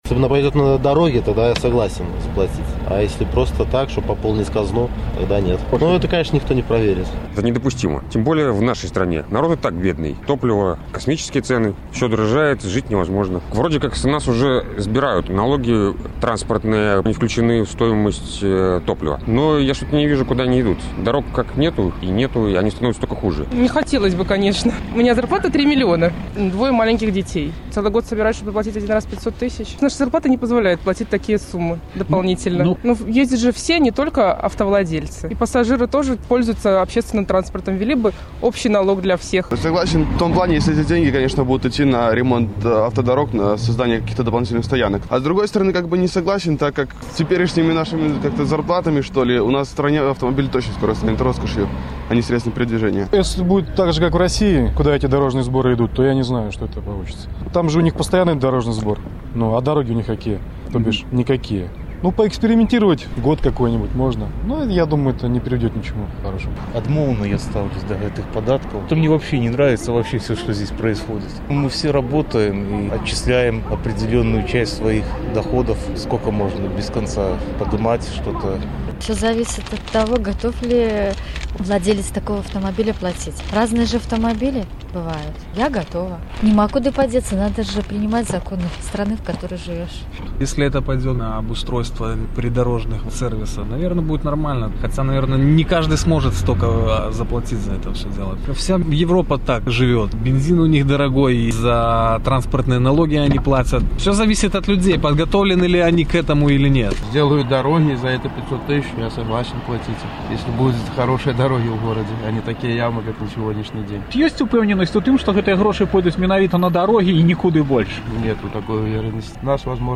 Адказвалі жыхары Магілёва.